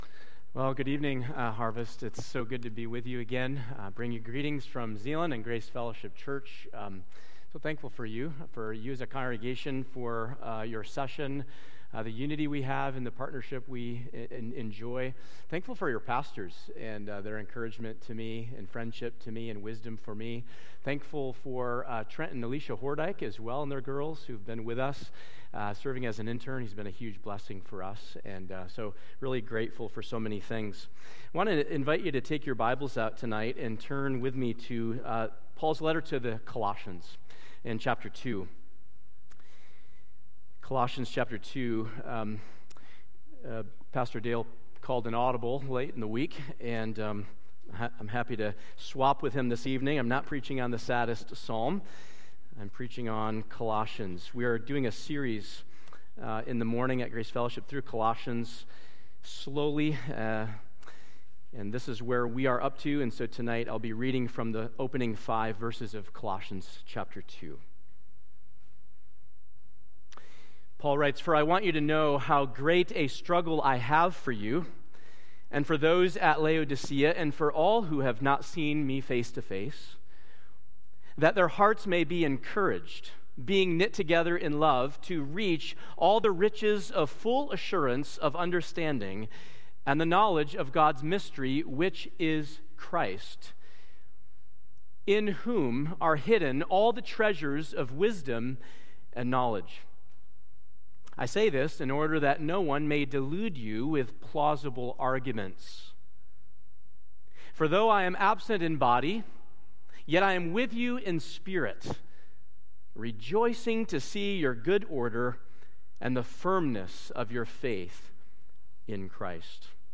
Sermons & Teaching